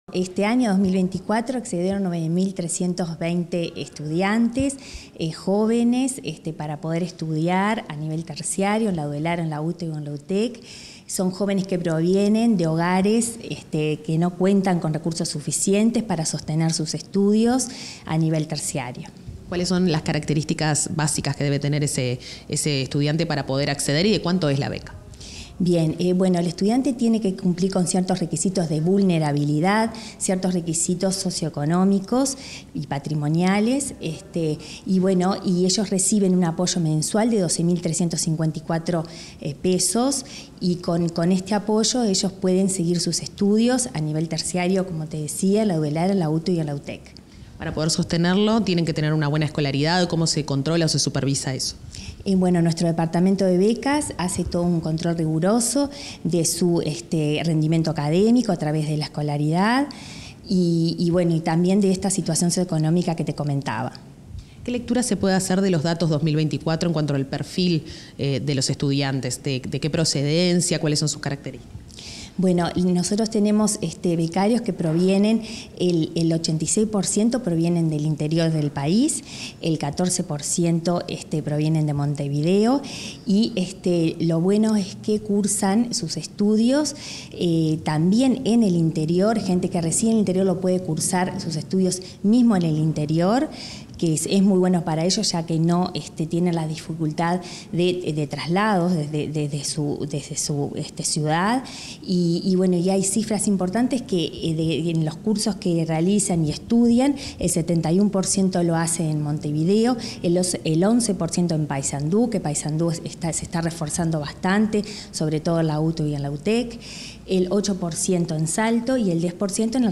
Entrevista a la presidenta del Fondo de Solidaridad, Rosario Cerviño
El Fondo de Solidaridad publicó la rendición de cuentas 2024, con los principales resultados sobre el otorgamiento de becas y la recaudación de aportes. Comunicación Presidencial dialogó con la presidenta de la referida dependencia, Rosario Cerviño, quien informó que este año 9.320 jóvenes estudian su carrera con becas del Fondo y el 92% integra la primera generación en su familia que accede a la educación terciaria.